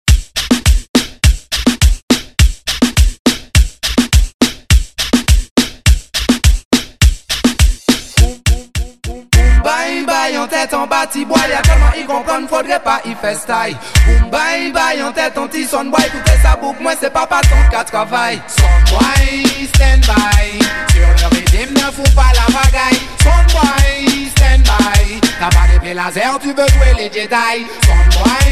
DJ